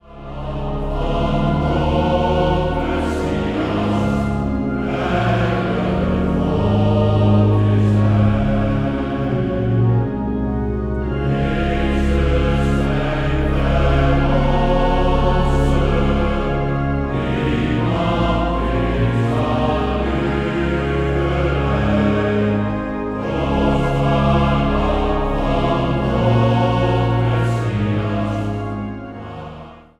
Mannenzang vanuit de Ichthuskerk te Urk
orgel.
Zang | Mannenzang